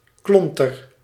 Ääntäminen
US : IPA : [lʌmp]